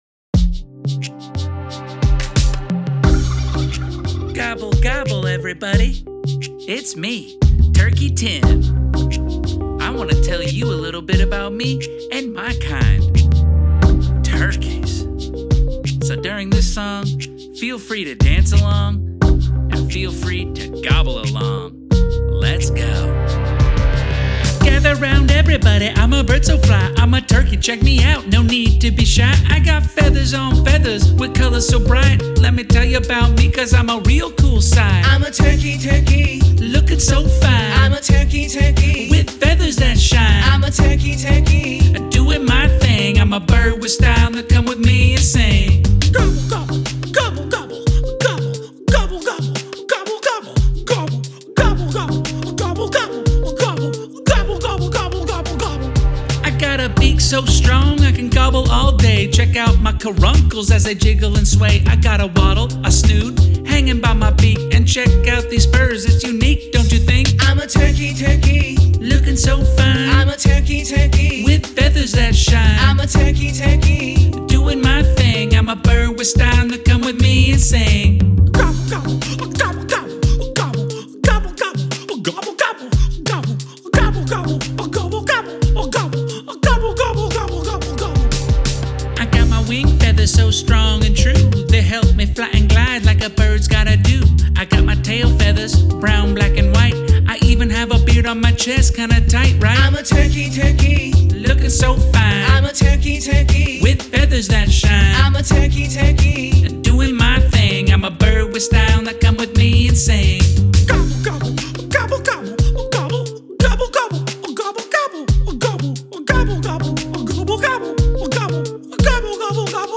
Children's